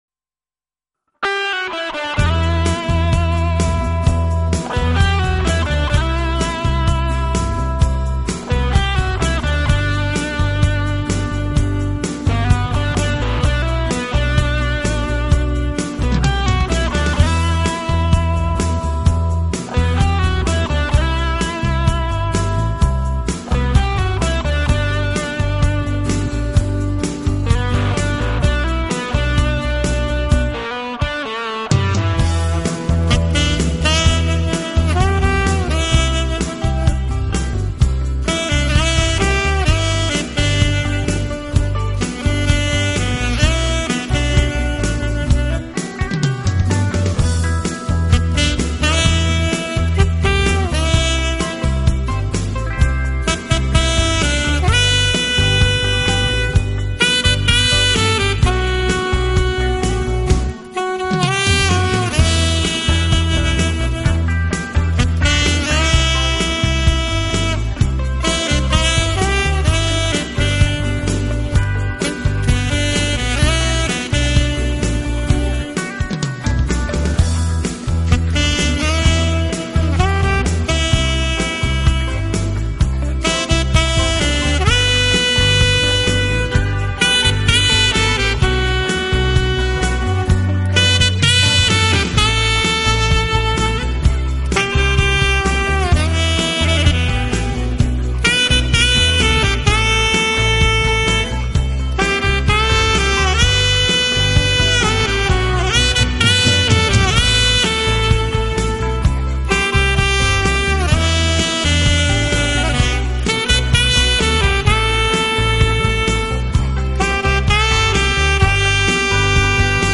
萨克斯，浪漫风情的完美代言人。